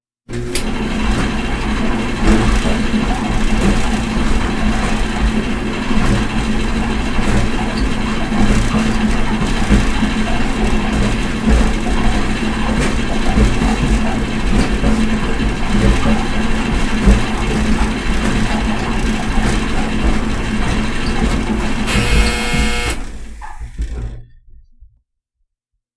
烘干机循环
描述：一个短的干燥周期。用我的变焦H5的xy胶囊和一个接触式麦克风录制。
Tag: 洗衣机 洗衣机 烘干机 洗衣机 洗衣